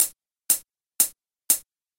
Here is the hi hat sample I selected:
The sample isn’t really anything special but it’s not overly bright, which can be an advantage – it will be very low in the mix compared to the other kick samples so don’t worry too much – play around to see what sounds you like.